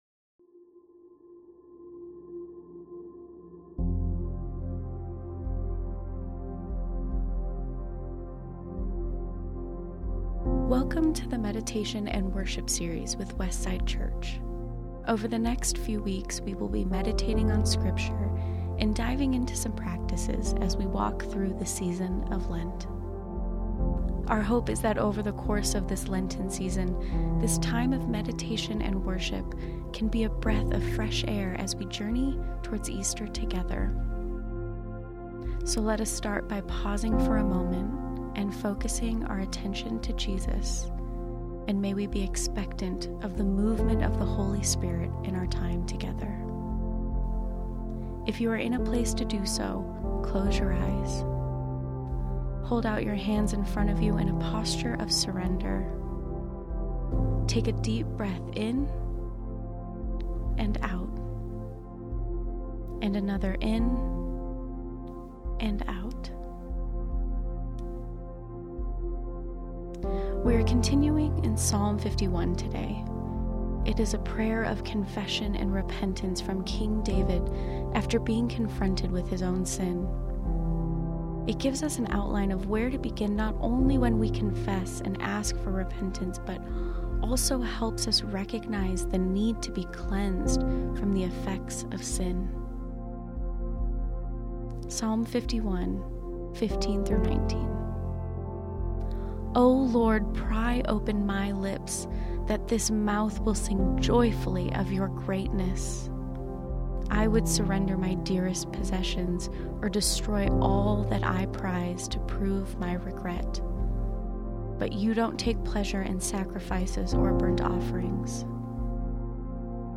Today we meditate on Psalm 51:15-19, the end of David's prayer of confession and spend a time in worship singing "How Great Thou Art"